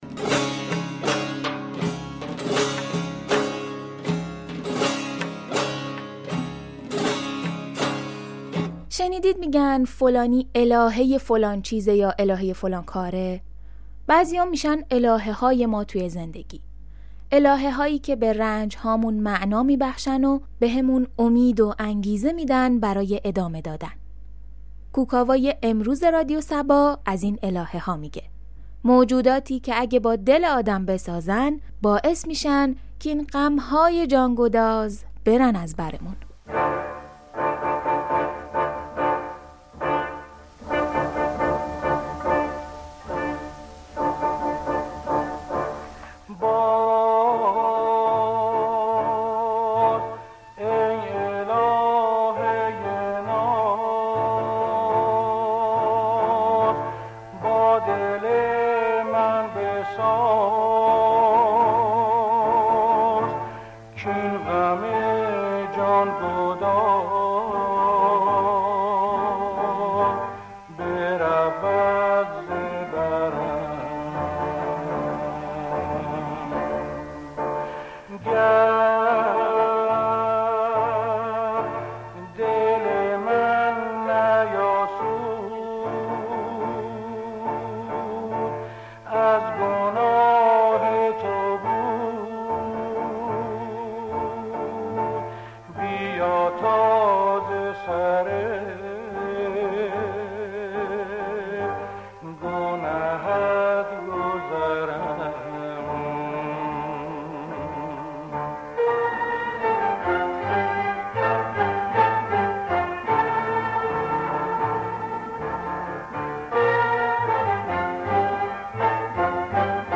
برنامه موسیقی محور